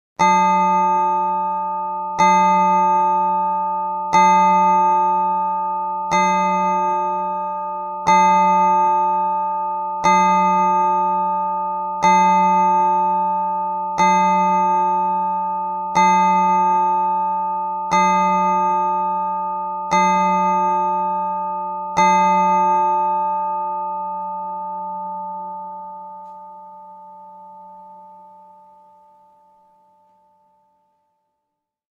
دانلود صدای زنگ ساعت قدیمی و بزرگ از ساعد نیوز با لینک مستقیم و کیفیت بالا
جلوه های صوتی